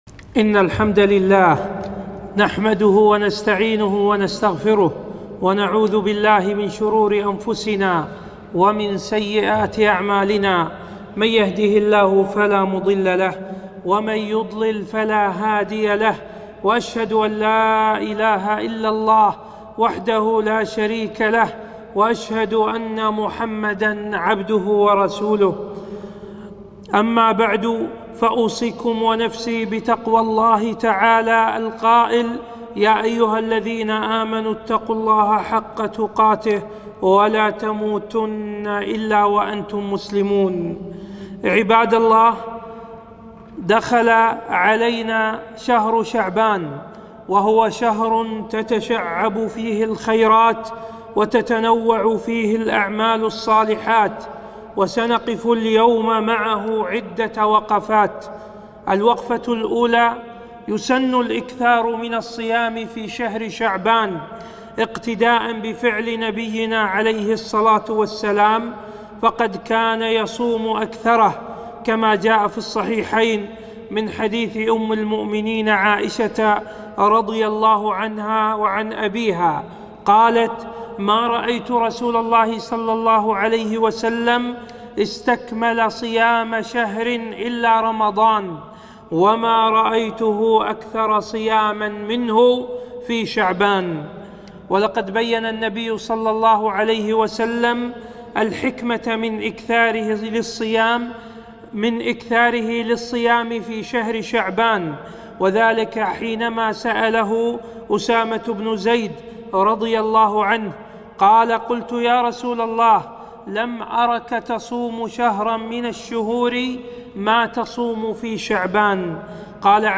خطبة - وقفات شرعية مع شهر شعبان